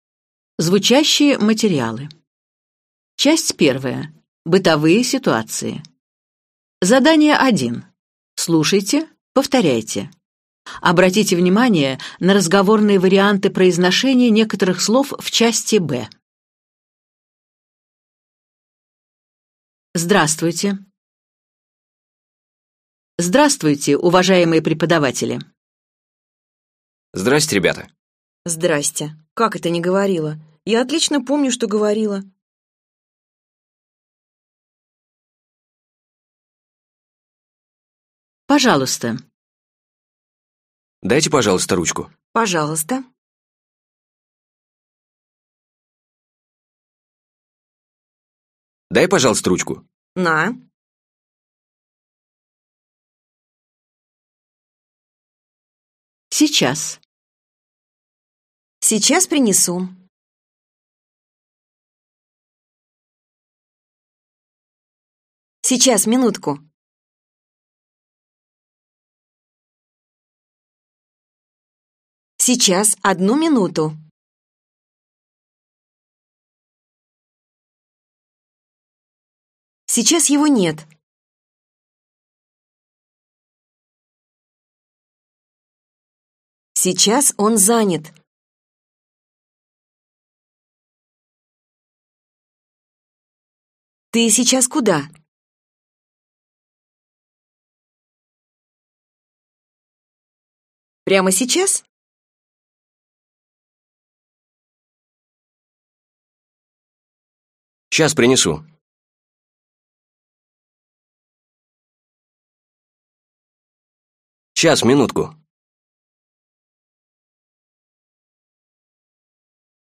Аудиокнига Живой русский. Выпуск 1 | Библиотека аудиокниг